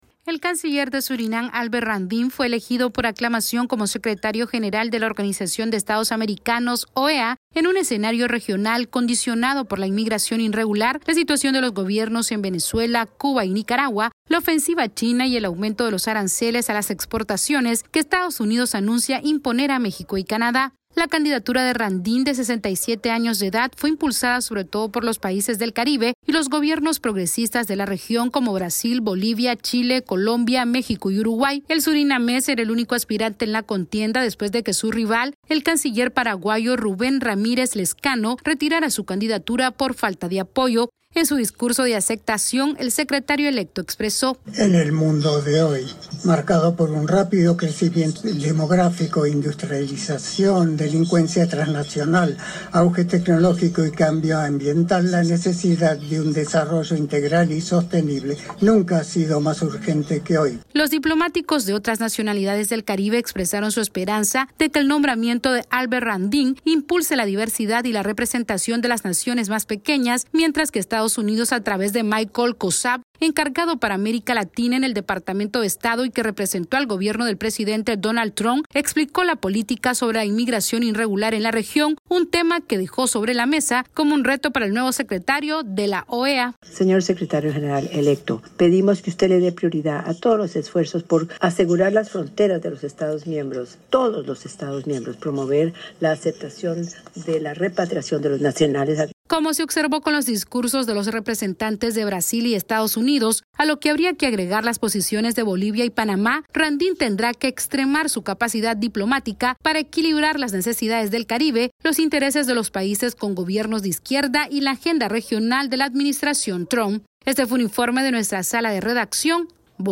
AudioNoticias
La Organización de los Estados Americanos, la OEA, eligió al ministro de Exteriores de Surinam, Albert Ramdin, como nuevo secretario general en un momento crucial para la política regional. Este es un informe de nuestra Sala de Redacción....